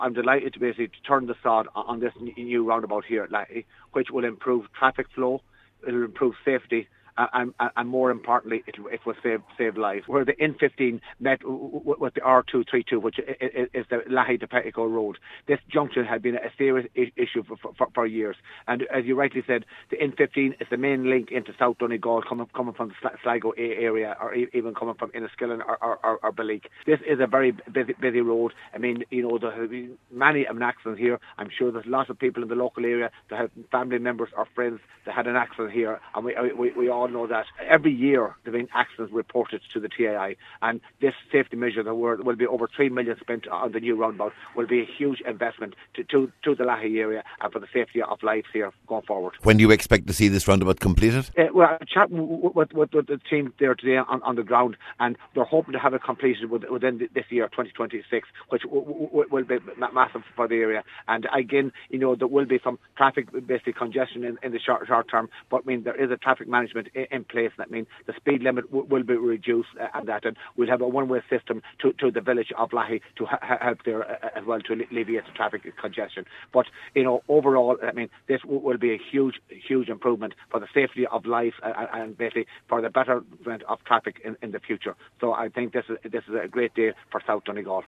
Cathaoirleach of the Donegal Municipal District Mícheal Naughton says it’s an important project………